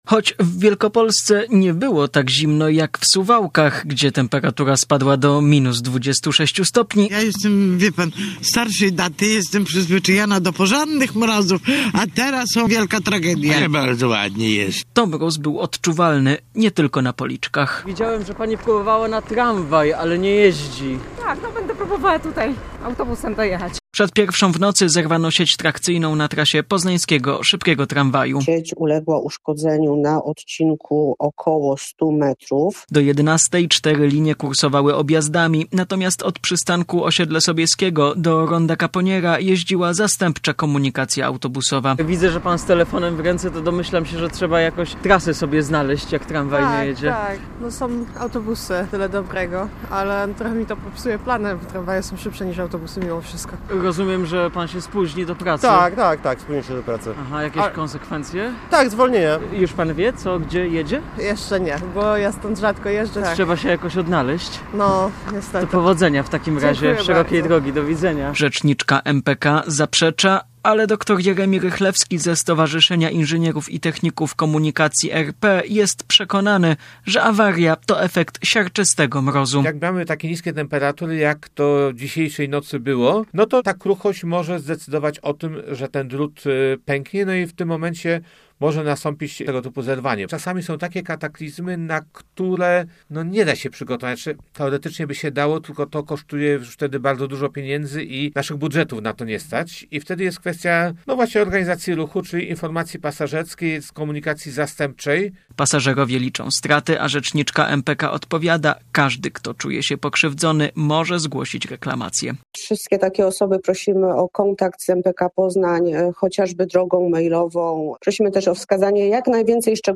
Z pasażerami czekającymi na przystanku Słowiańska rozmawiał nasz dziennikarz.